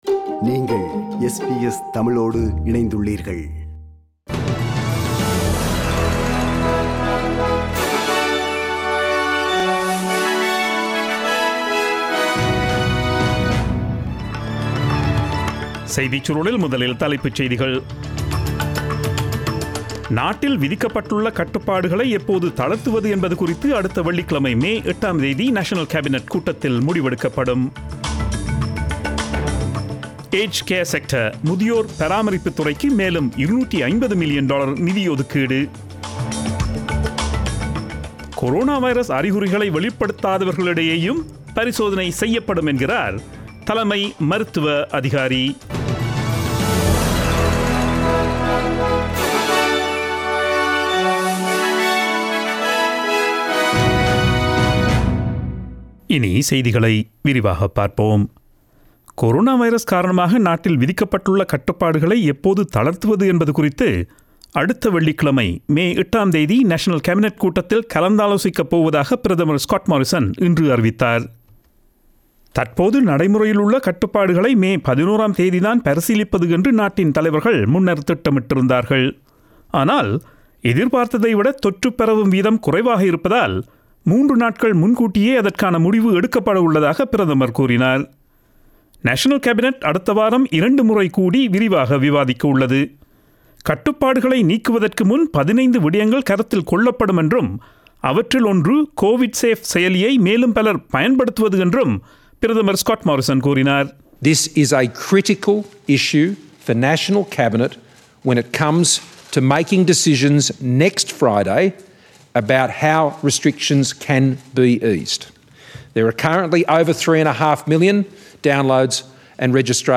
Australian news bulletin aired on Friday 01 May 2020 at 8pm.